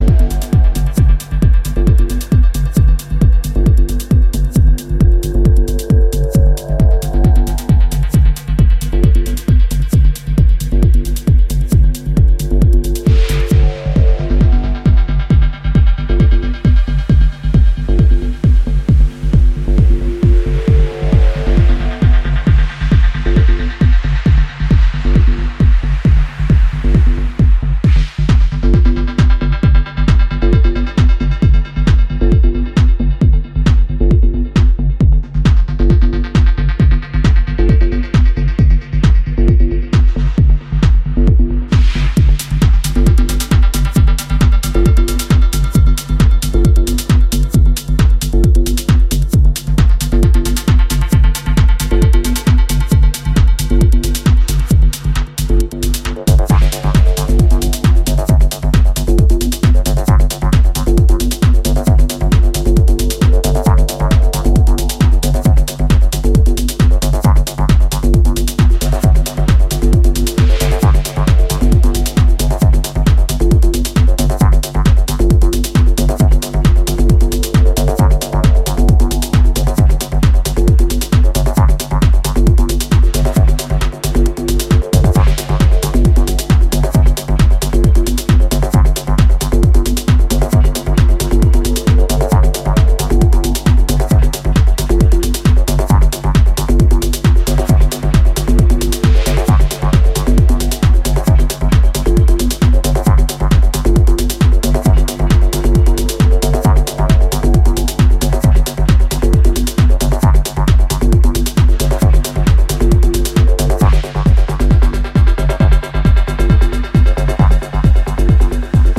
ディープ・テクノのミニマルなグルーヴにプログレッシヴ・トランスなサイケデリック性をミックスした
ディープにハメてくる感覚が以前よりも研ぎ澄まされてきてると感じさせます。